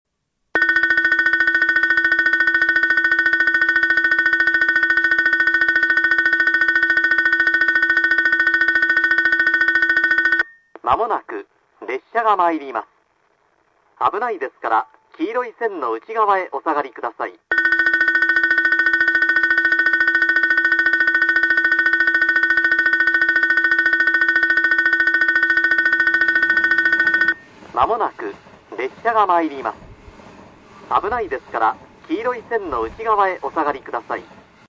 スピーカー：TOA（白）
音質：E
１番のりば 接近放送・男性 （上り・鳥栖方面） (169KB/34秒) 「ベル･･･」　　　まもなく列車がまいります、危ないですから黄色い線の内側へお下がりください。
上下線とも福北ゆたか線で多く使われる簡易的な放送です。ベルが長く、戻りが早いのが特徴です。